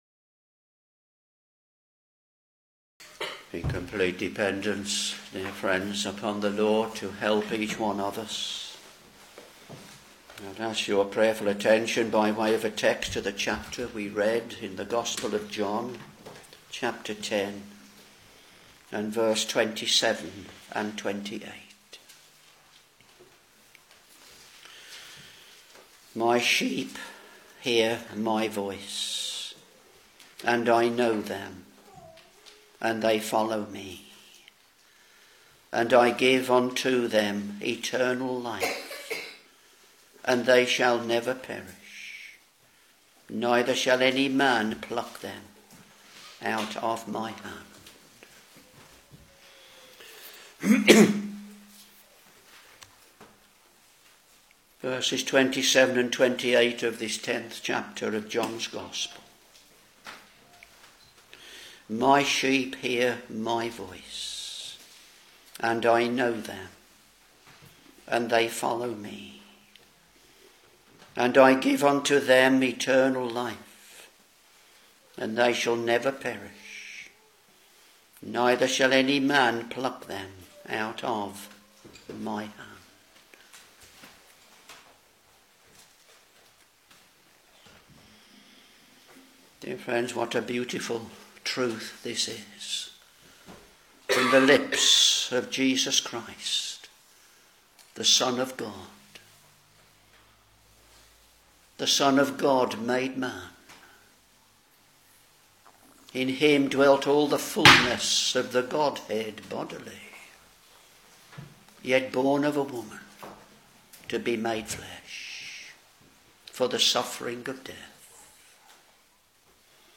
Sermons John Ch.10 v.27 & v.28